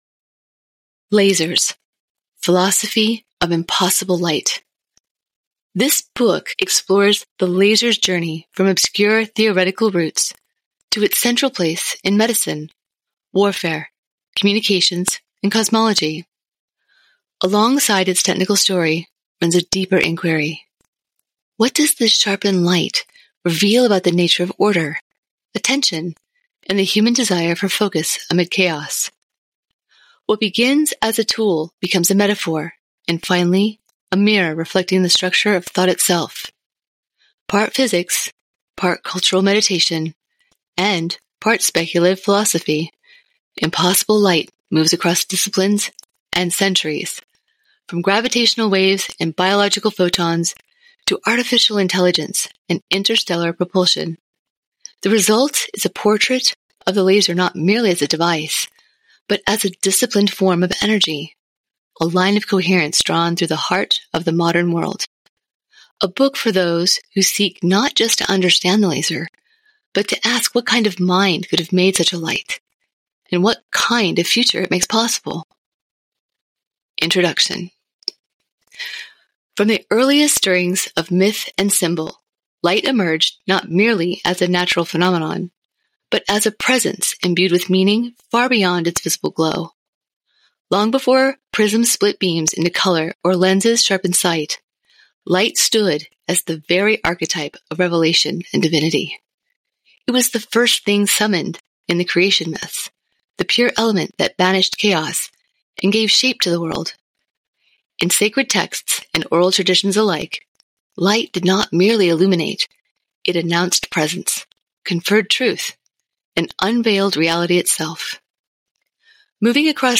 Rich chest resonance and a calm, medium-low pitch deliver complex medical, technical, and scientific content with confident pronunciation and clear context. Naturally approachable with deliberate pacing, ideal for material demanding precision, accuracy, and ease of comprehension.
VOICE ACTOR DEMOS
0414lasersdemo.mp3